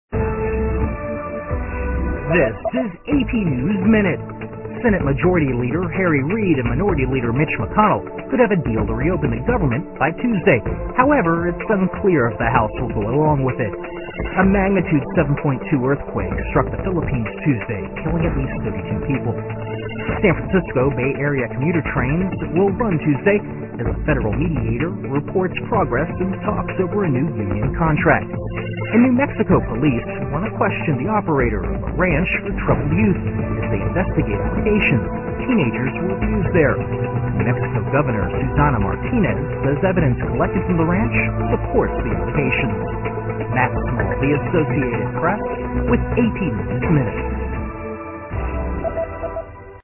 在线英语听力室美联社新闻一分钟 AP 2013-10-18的听力文件下载,美联社新闻一分钟2013,英语听力,英语新闻,英语MP3 由美联社编辑的一分钟国际电视新闻，报道每天发生的重大国际事件。电视新闻片长一分钟，一般包括五个小段，简明扼要，语言规范，便于大家快速了解世界大事。